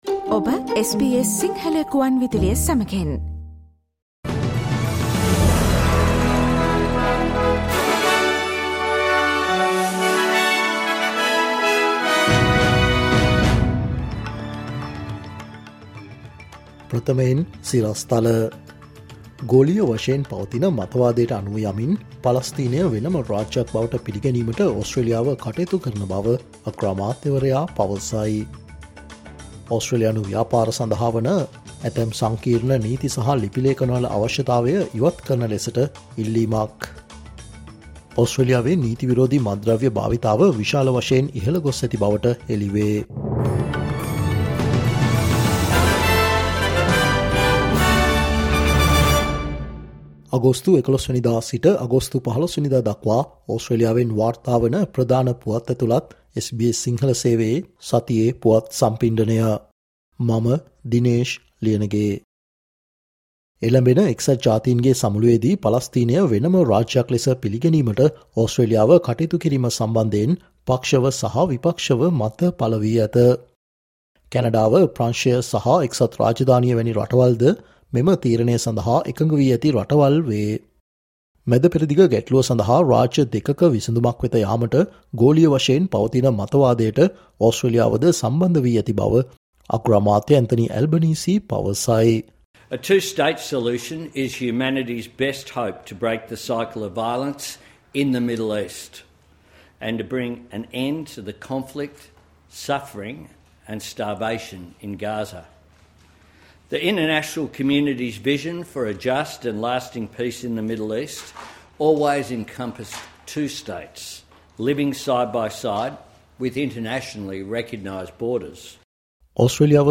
අගෝස්තු 11 සිට අගෝස්තු 15 වනදා දක්වා වන මේ සතියේ ඕස්ට්‍රේලියාවෙන් වාර්තාවන පුවත් ඇතුලත් සතියේ පුවත් ප්‍රකාශයට සවන් දෙන්න